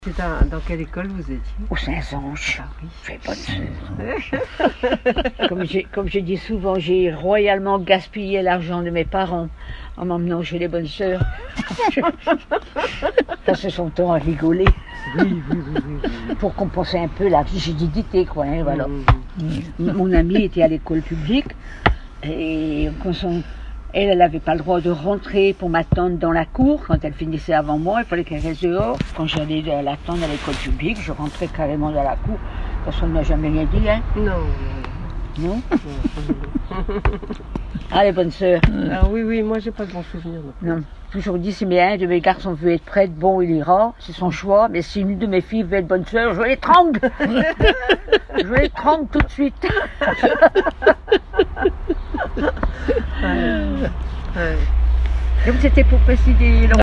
Témoignages et chansons
Catégorie Témoignage